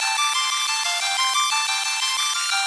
Index of /musicradar/shimmer-and-sparkle-samples/90bpm
SaS_Arp04_90-A.wav